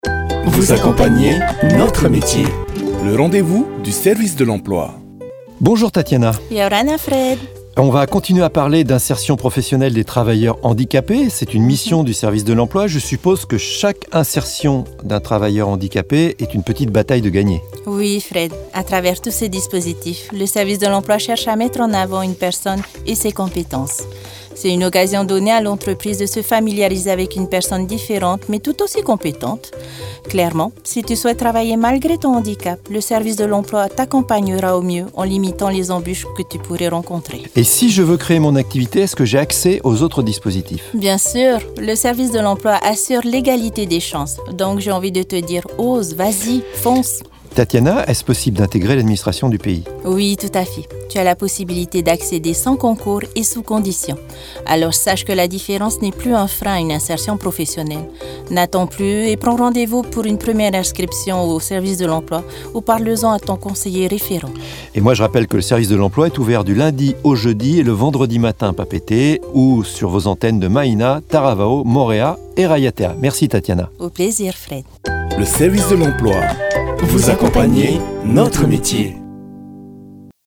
ITV-SERVICE-DE-LEMPLOI-API-FM-021-SITH-N°2.mp3